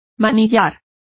Complete with pronunciation of the translation of handlebars.